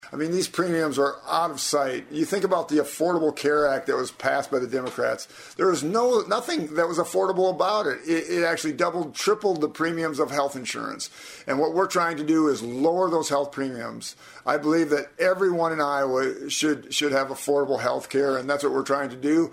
CONGRESSMAN RANDY FEENSTRA TOLD KSCJ NEWS THIS WEEK IOWANS WANT LOWER HEALTH CARE COSTS: